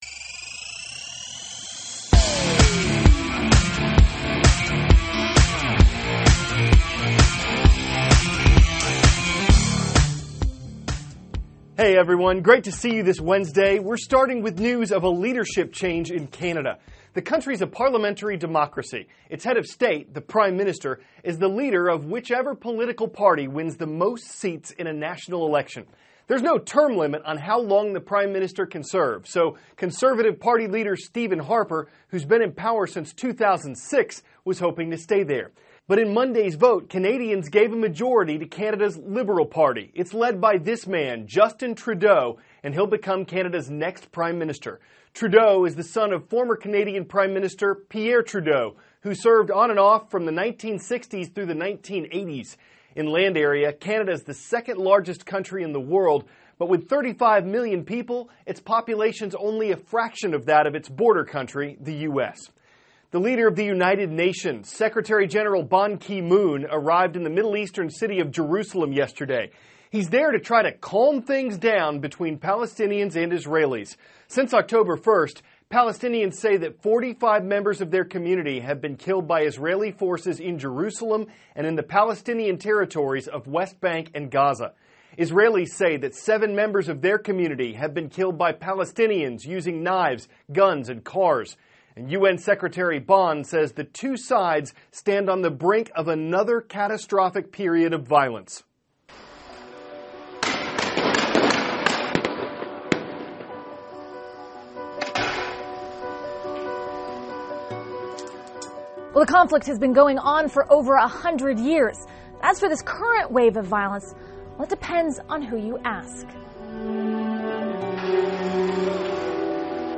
(cnn Student News) -- October 21, 2014 A Leadership Change in Canada; U.N. Leader Visits Israel, Palestine Territories Amid Tensions; A Preview of Where Technology Could Take Air Travel THIS IS A RUSH TRANSCRIPT.